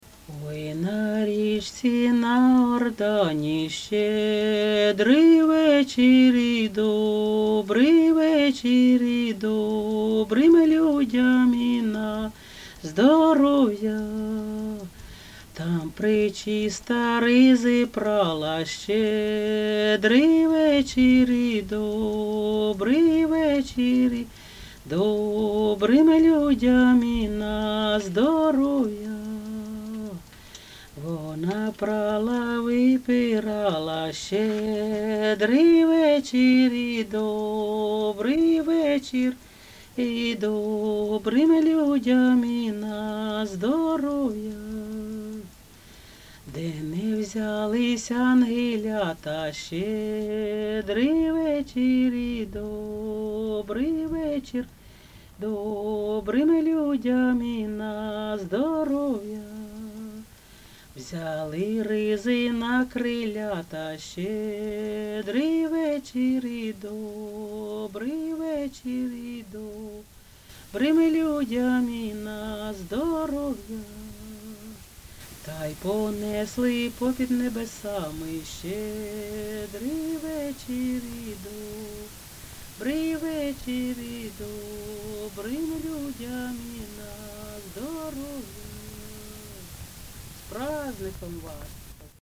ЖанрЩедрівки
Місце записус. Серебрянка, Артемівський (Бахмутський) район, Донецька обл., Україна, Слобожанщина